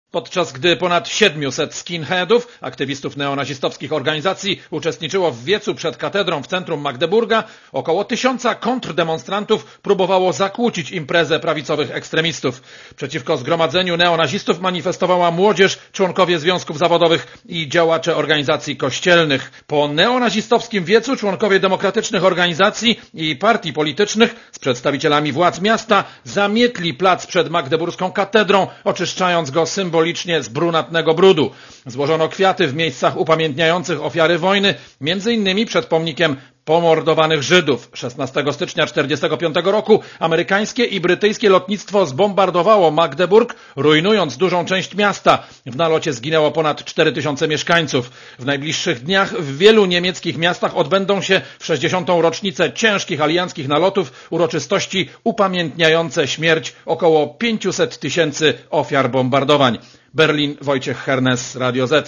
Korespondencja z Niemiec W tym samym czasie 1000 osób - młodzież, związkowcy i przedstawiciele Kościołów - demonstrowało przeciwko wykorzystywaniu przez skrajną prawicę historycznej rocznicy.